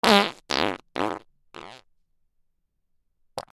おなら
/ J｜フォーリー(布ずれ・動作) / J-25 ｜おなら・大便